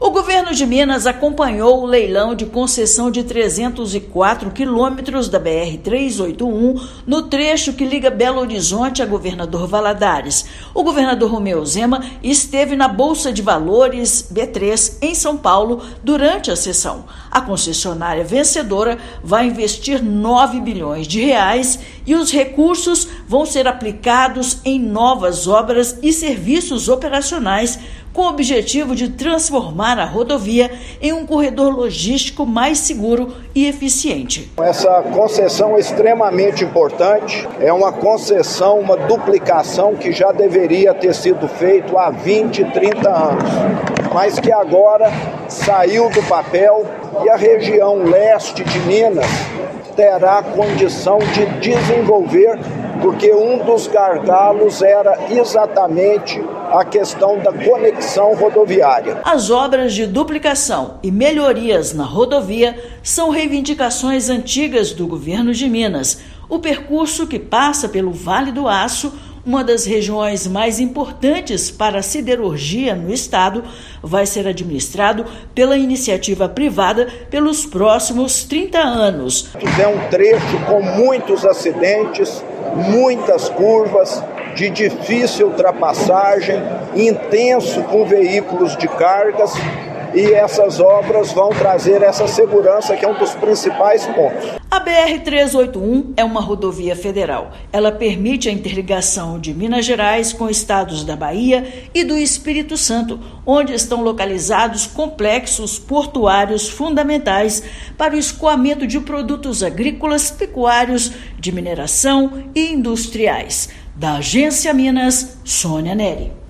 Concessionária vencedora do certamente, realizado nesta quinta-feira (29/8), vai investir R$ 9 bilhões; obras vão melhorar mobilidade na região Leste e gerar mais de 70 mil empregos. Ouça matéria de rádio.